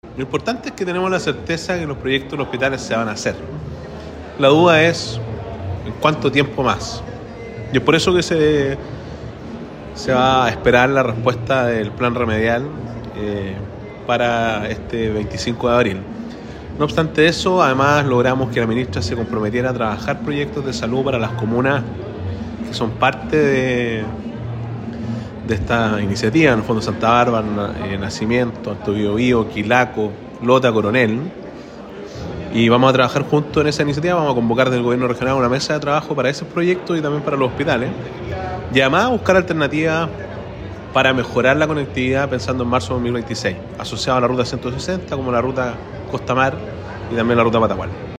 Por su parte, el gobernador regional Sergio Giacaman valoró que “la ministra se comprometiera a trabajar proyectos de salud para las comunas que son parte de esta iniciativa” y también se buscarán “alternativas para mejorar la conectividad, pensando en marzo de 2026, con opciones como la Ruta Costamar y la Ruta Patagual en la zona de la Ruta 160”.